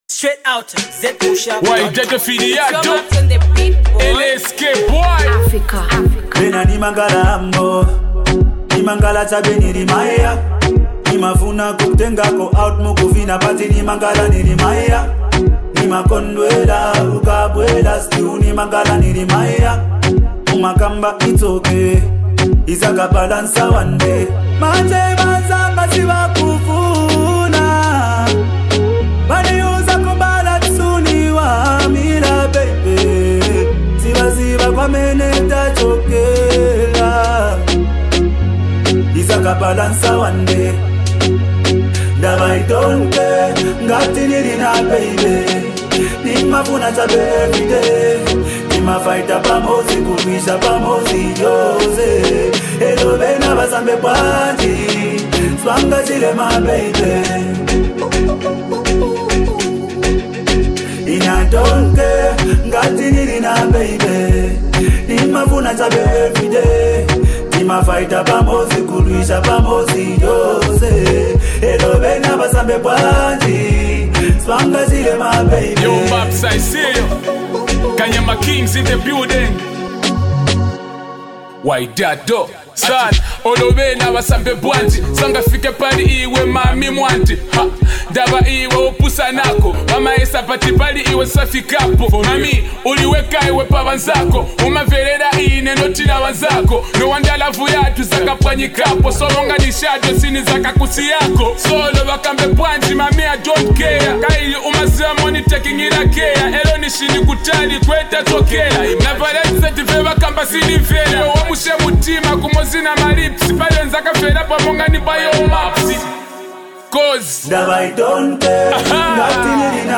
delivers murder bars